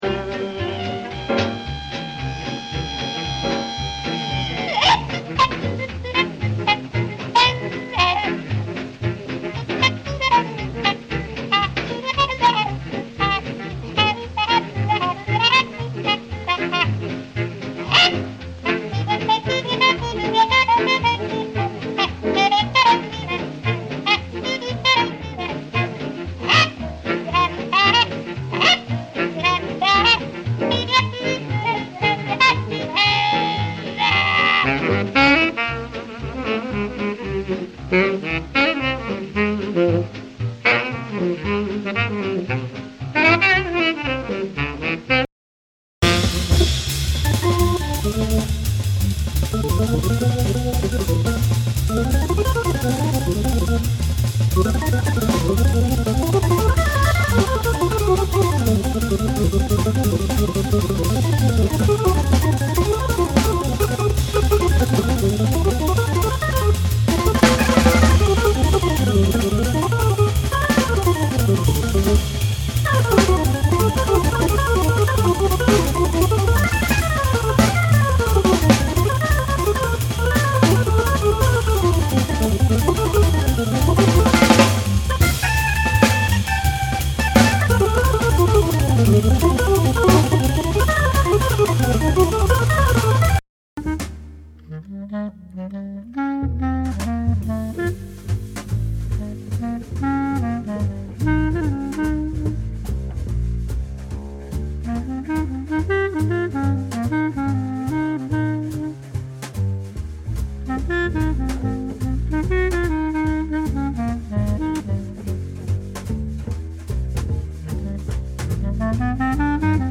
j'ai laissé un petit blanc entre chaque titre bonne écoute.